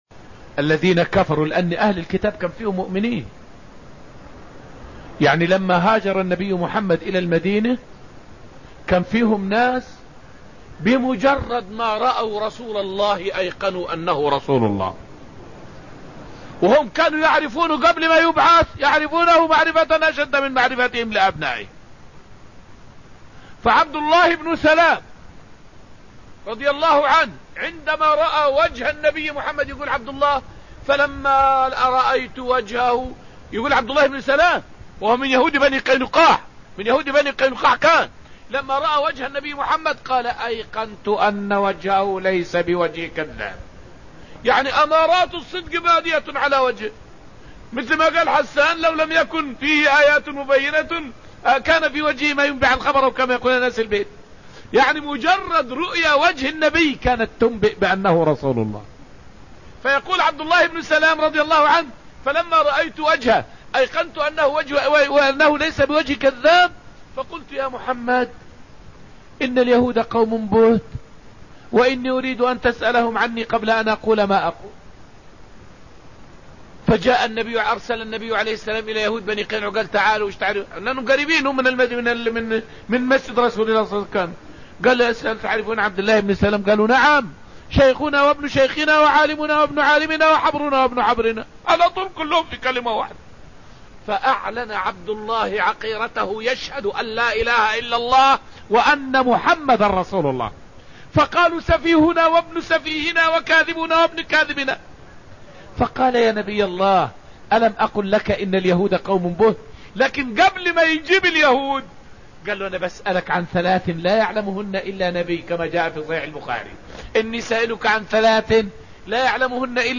تفسير دروس المسجد النبوي سورة الحشر قصص الصحابة والتابعين
فائدة من الدرس التاسع من دروس تفسير سورة الحشر والتي ألقيت في المسجد النبوي الشريف حول قصة إسلام عبد الله بن سلام.